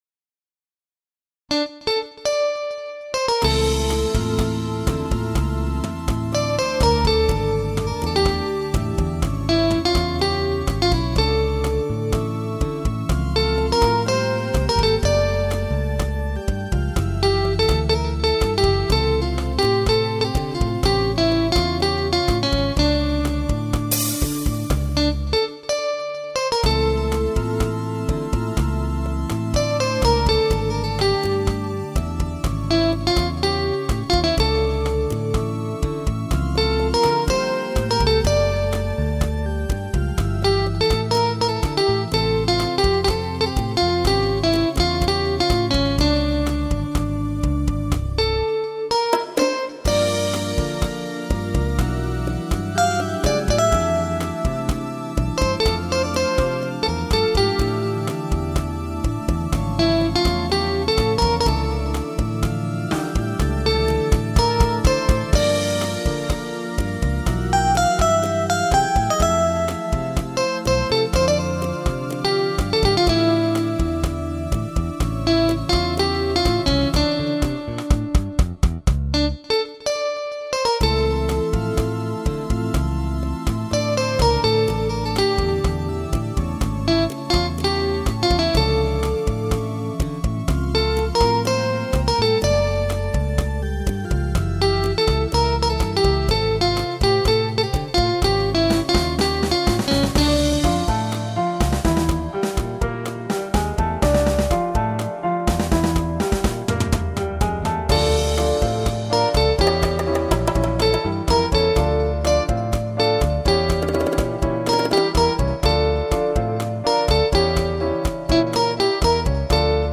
Home > Music > Jazz > Bright > Smooth > Medium